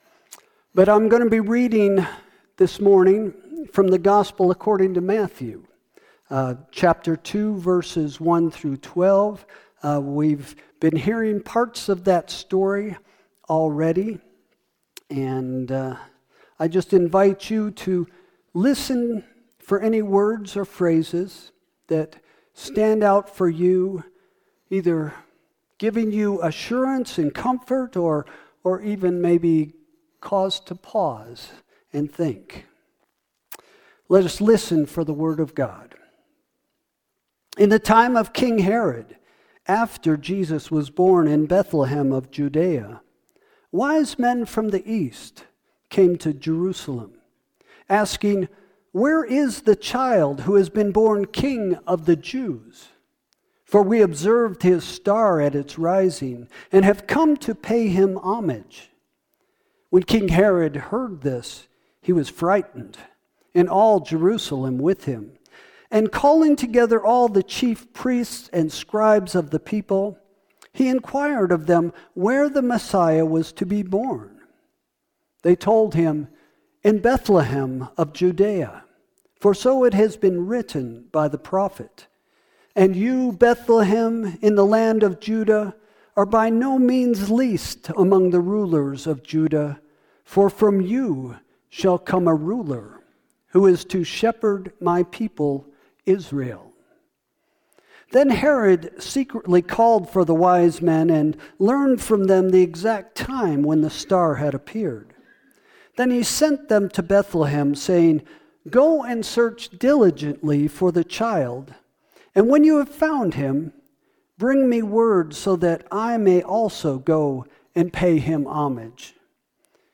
Sermon – February 1, 2026 – First Christian Church